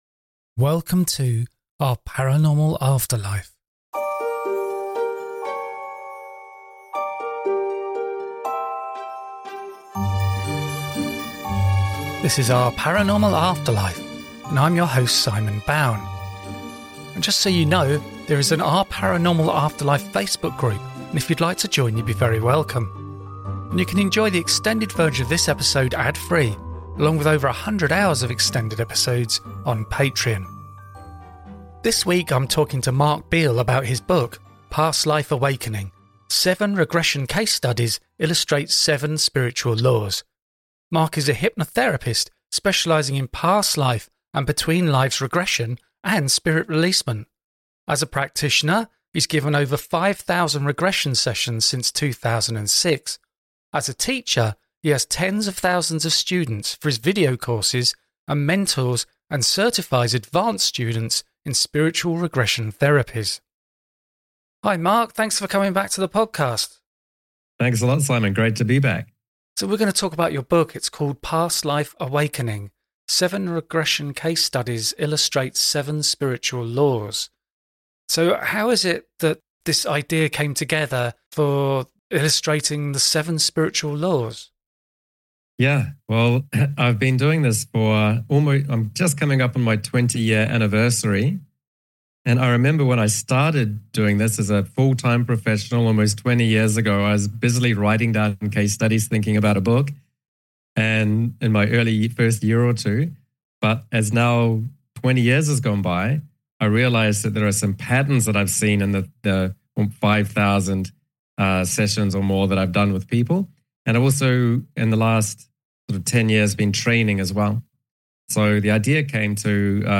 The conversation also touches on the fascinating world of mediumship and the insights it can provide into consciousness after death.